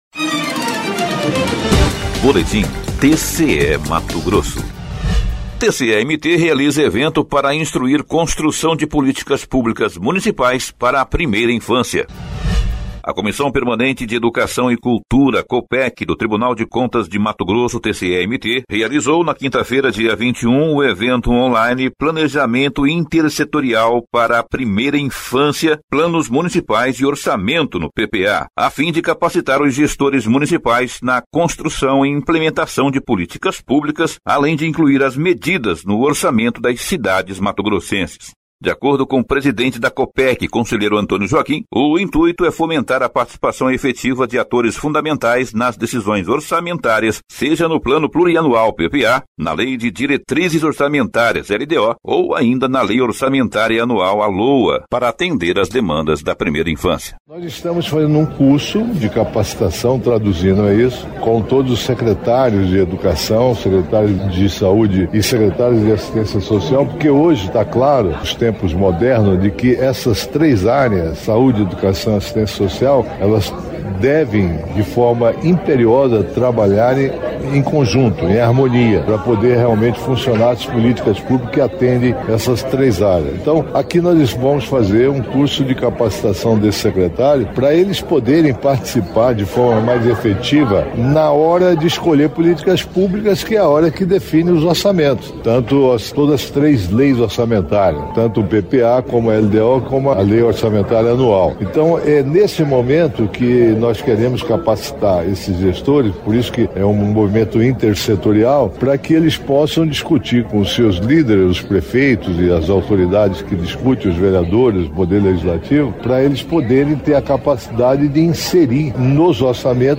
Sonora: Antonio Joaquim – conselheiro presidente da Copec do TCE/MT
Sonora: Guilherme Antonio Maluf – conselheiro presidente da COPSPAS do TCE/MT
Sonora: Túlio Duailibi Alves de Souza - juiz de direito do TJMT